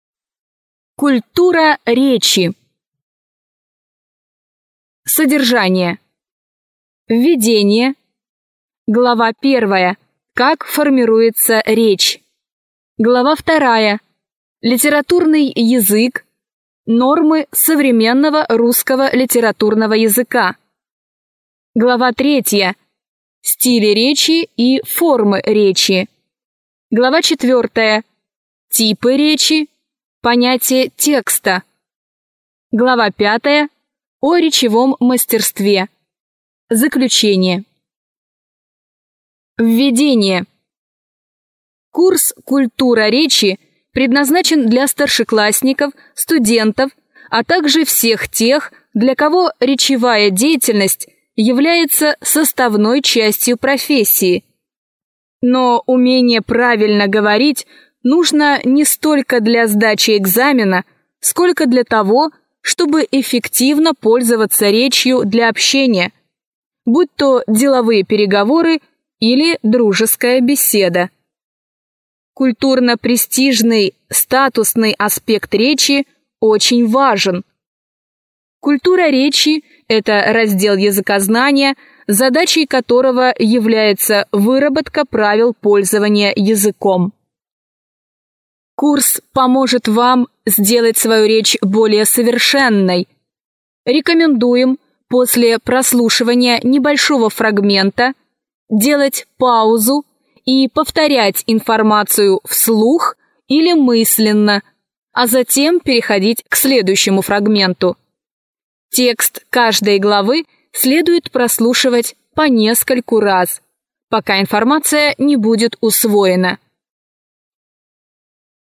Аудиокнига Культура речи. Как правильно и грамотно говорить по-русски | Библиотека аудиокниг
Прослушать и бесплатно скачать фрагмент аудиокниги